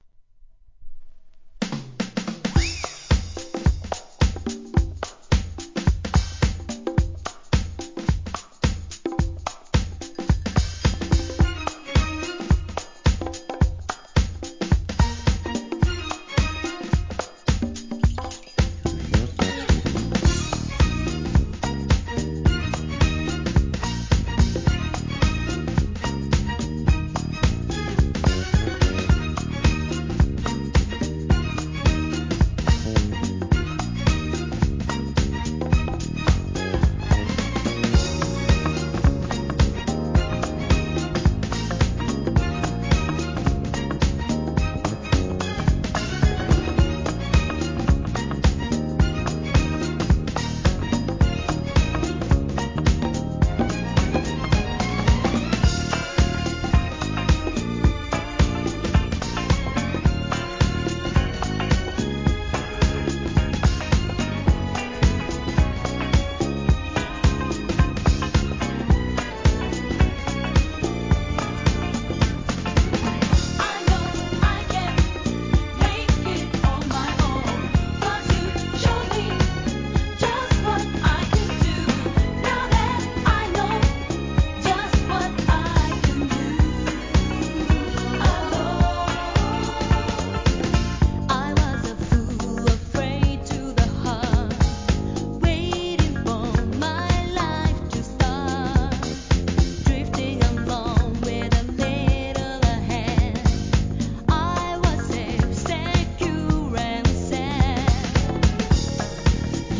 JAPANESE HIP HOP/R&B
通常シングルは日本語詞でしたがこちらは英語詞バージョン!!